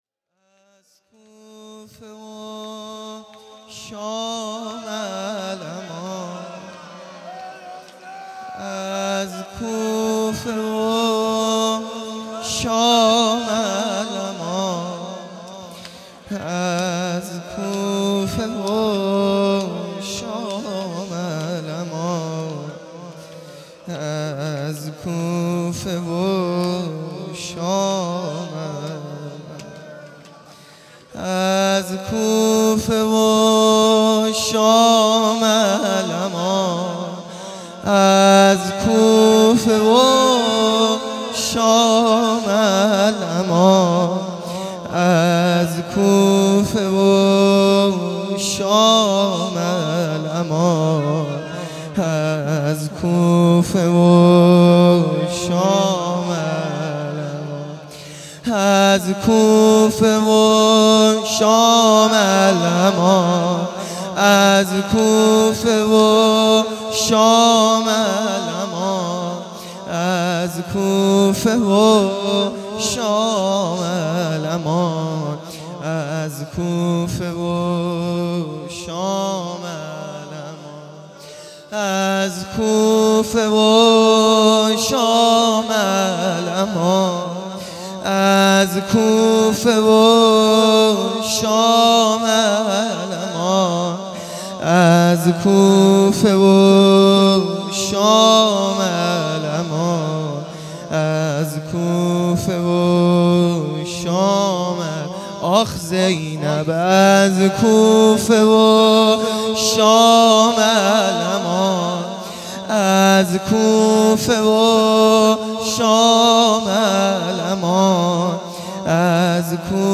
زمینه | از کوفه و شام الامان